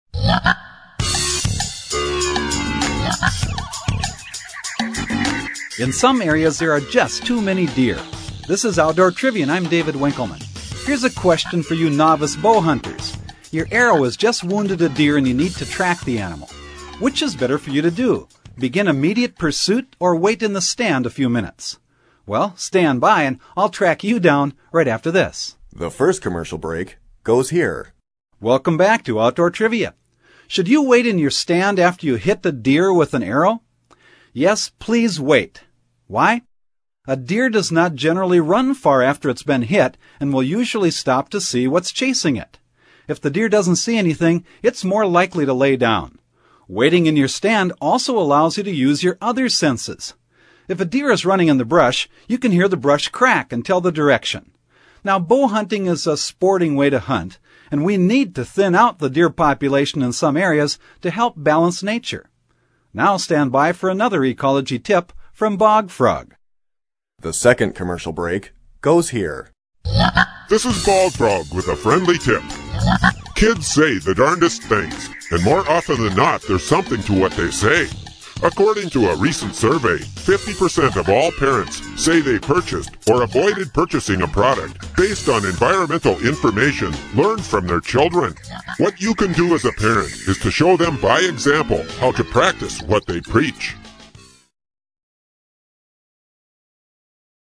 In fact, the question and answer trivia format of this program remains for many people, a most enjoyable, yet practical method of learning.
Programs are 2 1/2 minutes long, including commercial time. Bog Frog’s Tips conclude each program.
Bog Frog's voice is distinctive and memorable, while his messages remain positive and practical, giving consumers a meaningful symbol to remember.